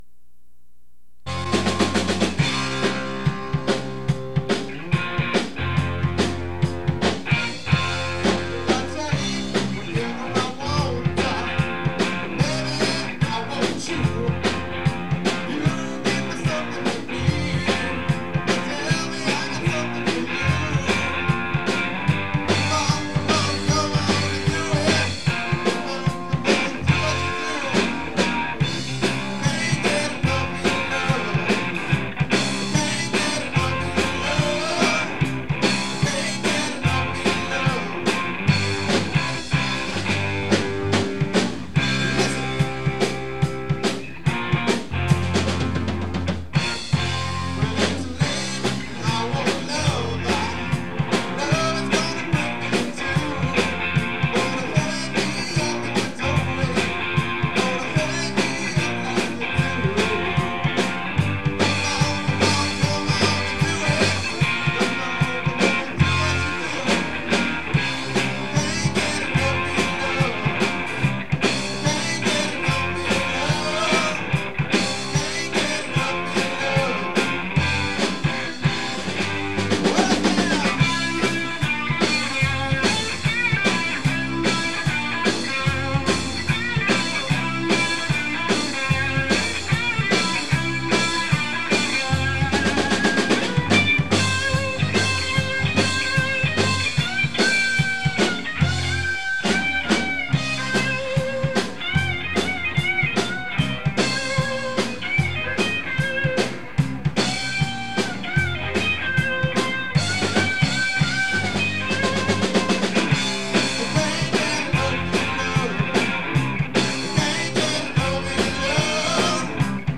lead guitar
drums